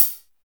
ROCK 1 2.wav